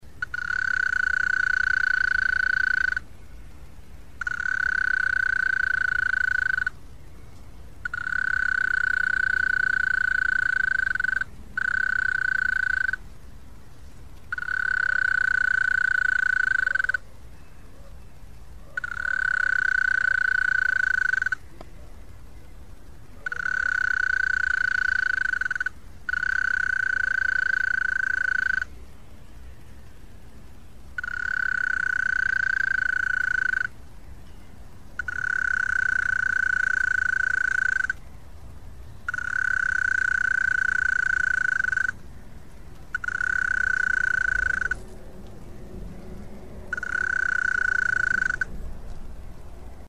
На этой странице собраны звуки медведки — стрекотание и другие характерные шумы, которые издает это насекомое.
Звук медведки в огороде